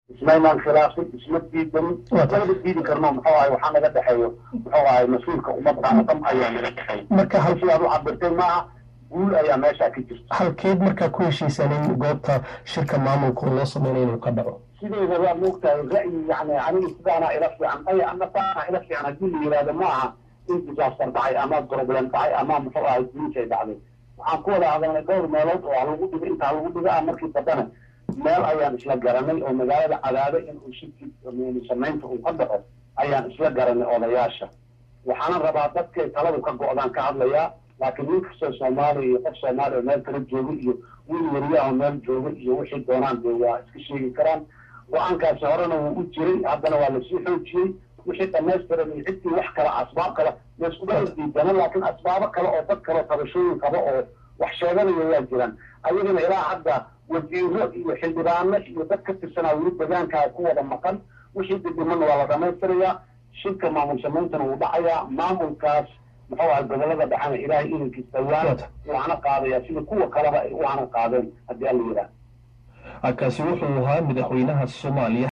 Muqdisho–Xasan Sheekh oo waraysi siiyay idaacada codka Maraykanka ayaa si cadho leh u sheegay in wadahadaladii maamul u samaynta gobolada dhexe ay fashilantey kadib markii odayadu diideen in ay tagaan tuulada Cadaado oo markii hore sida muuqata ay ku heshiiyeen beelihiisa Hawiye.